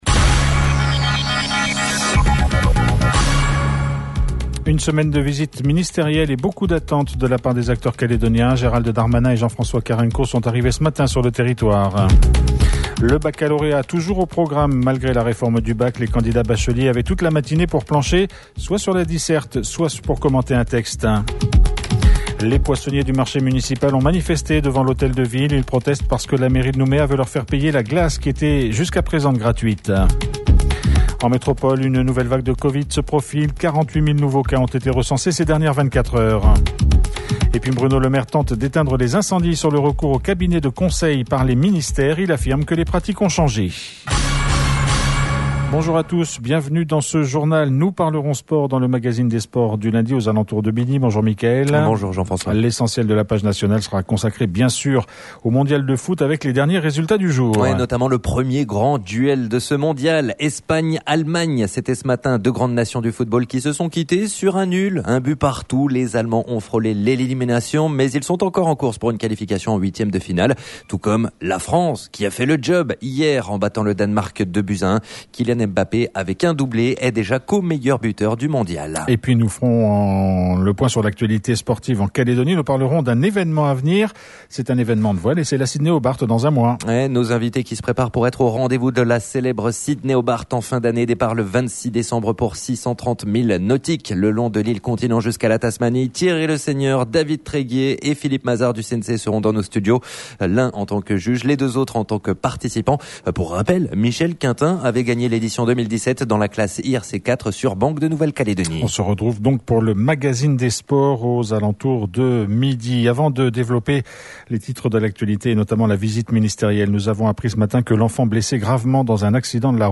JOURNAL : LUNDI 28/11/22 (MIDI)